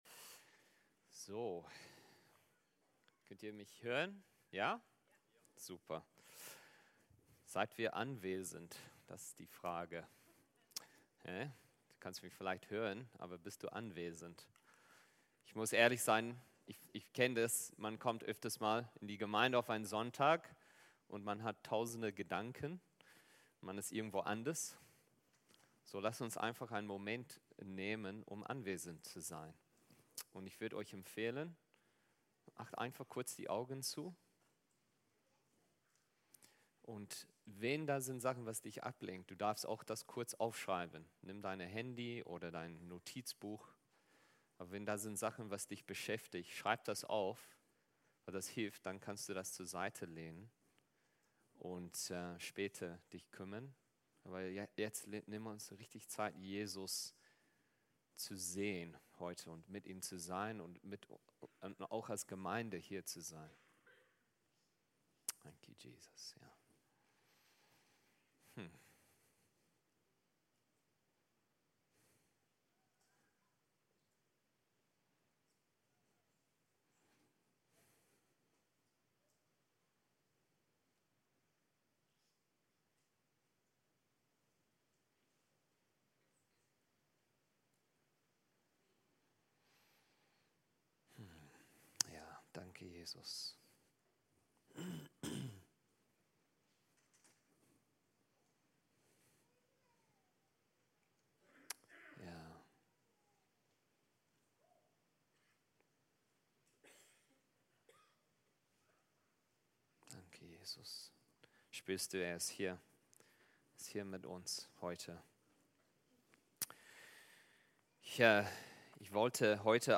Podcast unserer Predigten